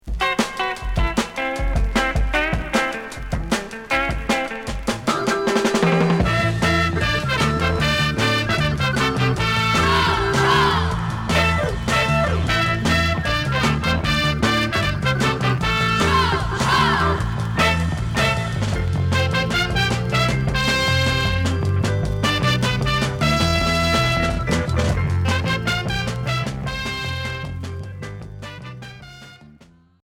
Jerk